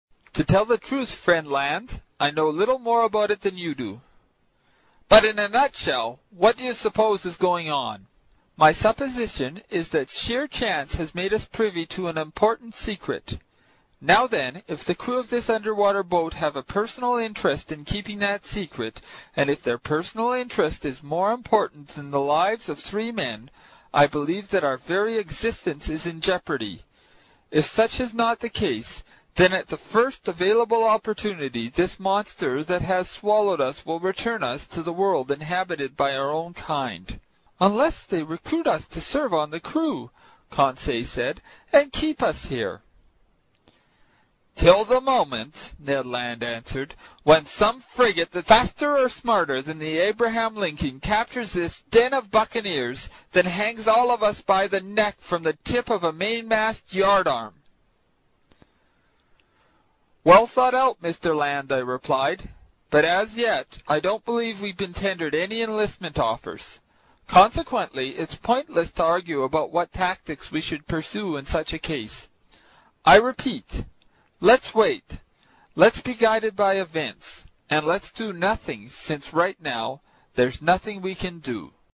英语听书《海底两万里》第123期 第9章 尼德·兰的愤怒(8) 听力文件下载—在线英语听力室